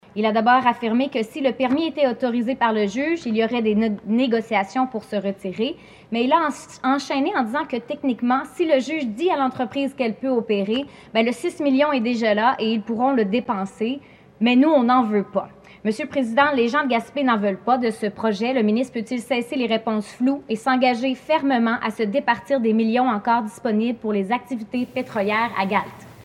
Le sujet a rebondi de nouveau mercredi à l’Assemblée nationale alors que, la députée de Gaspé, Méganne Perry Mélançon a demandé au ministre de l’Économie, Pierre Fitzgibon, comment il allait faire pour récupérer l’argent investi dans Gaspé Énergies.
La députée Méganne Perry Mélançon  :